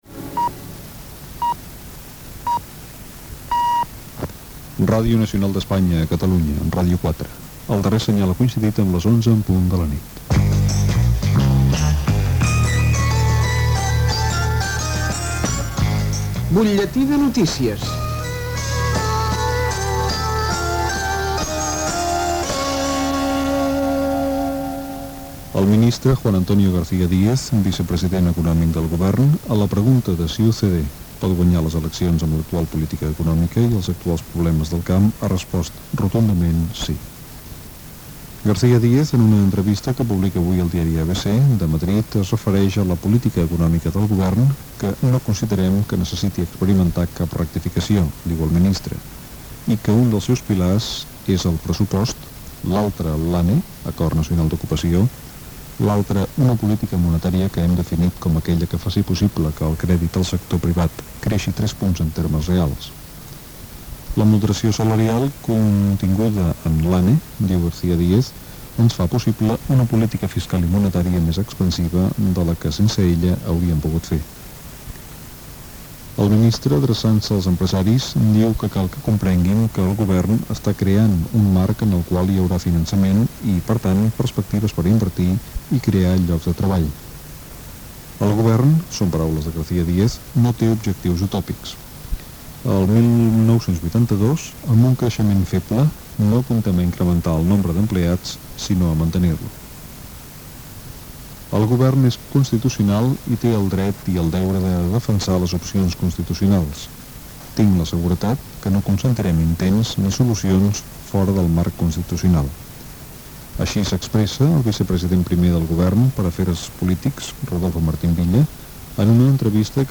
Butlletí de notícies. Política econòmica del govern, festival musical Bach, travessa.
Informatiu